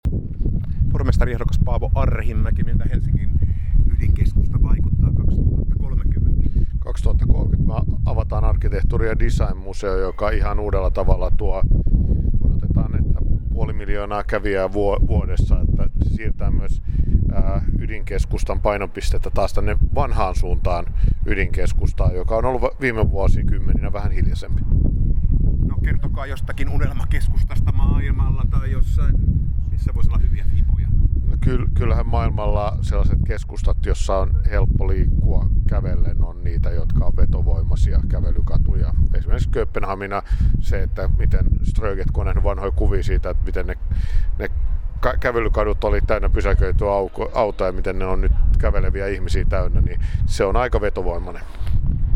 Pormestaripaneelissa Sofia Helsingissä kuultiin 26.3. ehdokkaita.